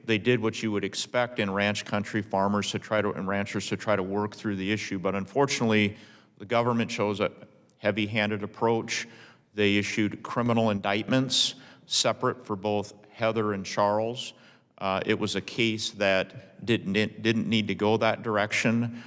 WASHINGTON, D.C.(KELO)- Attorney General Marty Jackley testified before a congressional committee Tuesday…in a hearing called ‘Farming on Trial”.